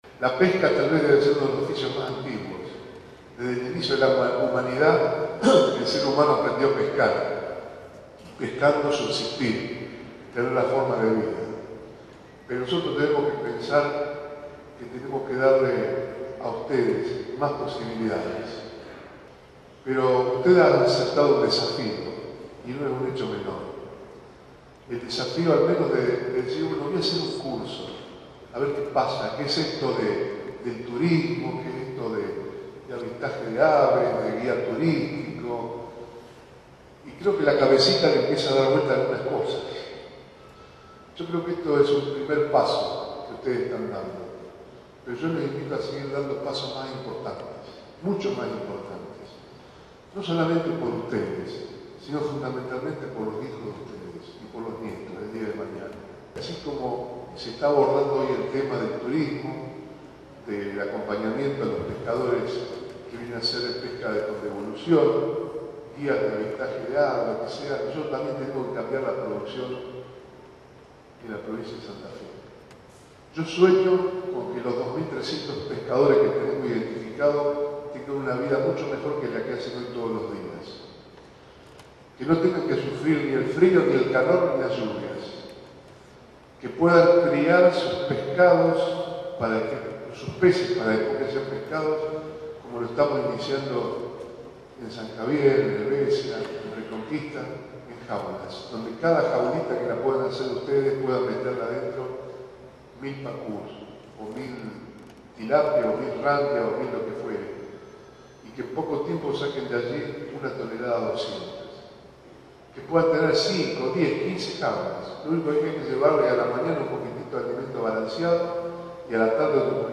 “Sueño con que 2.300 pescadores de Santa Fe tengan una mejor vida. Tenemos que cambiar entre todos la actividad pesquera en la provincia de Santa Fe”, expresó el gobernador Antonio Bonfatti en un acto en el salón Blanco de la Casa de Gobierno, en Santa Fe, al entregar las credenciales que habilitan a los guías de pesca deportiva con fines turísticos en todo el territorio santafesino.